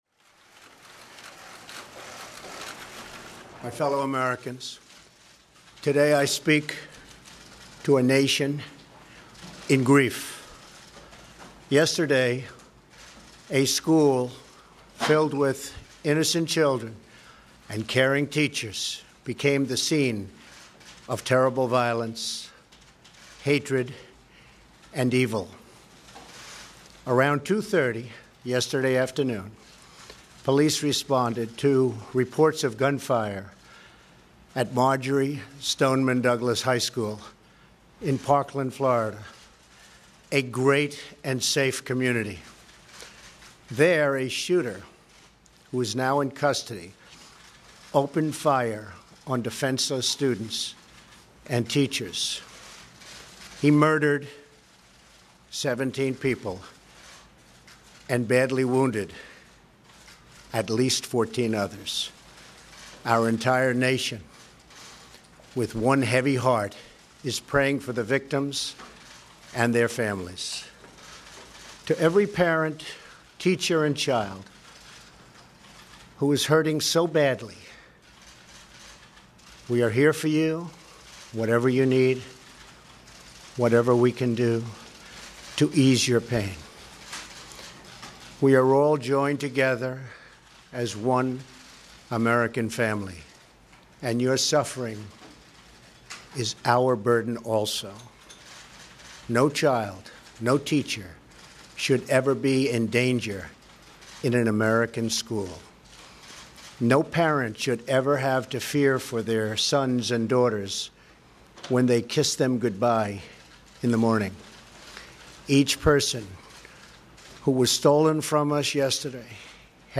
U.S. President Donald Trump delivers a short statement to the nation about the Parkland, Florida school shooting